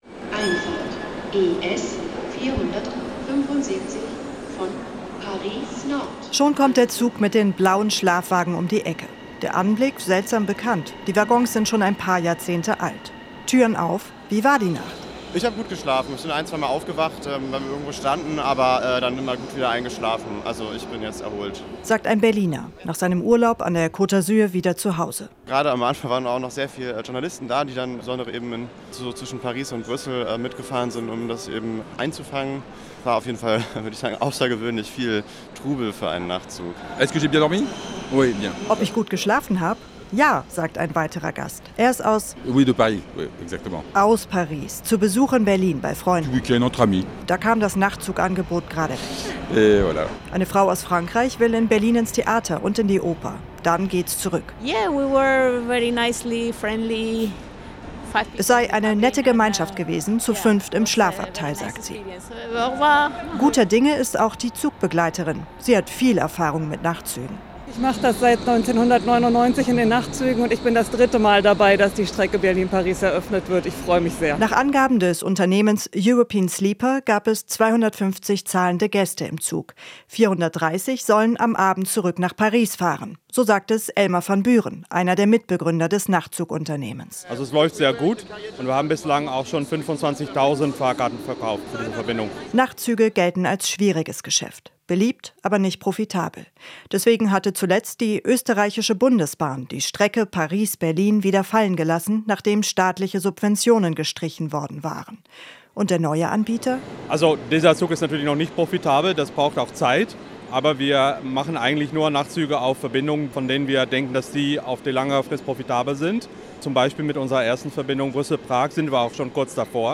war der Ankunft des Nachtzugs dabei.